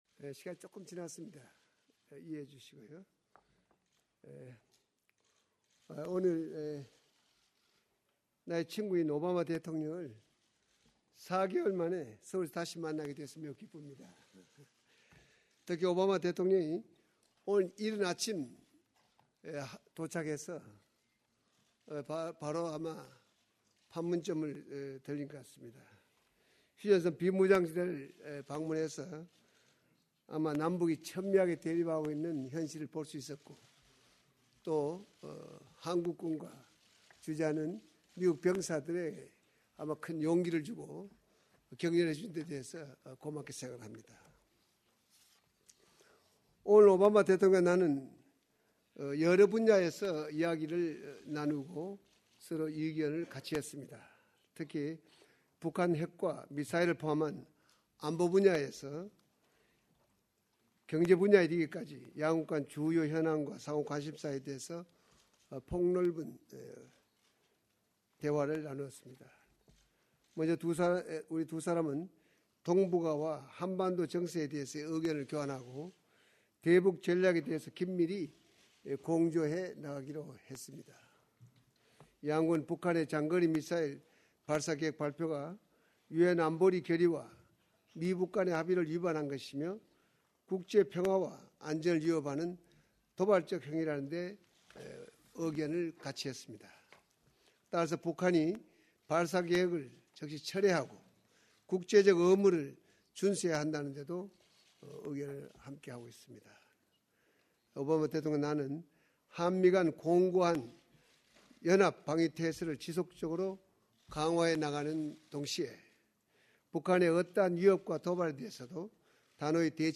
President Obama and Korean President Lee Myung-bak (through an interpreter) speak at a press conference in Seoul. After describing the essence of their talks on topics such as North Korea's nuclear weapons development, bilateral trade, and other issues of mutual interest, they take questions from the media.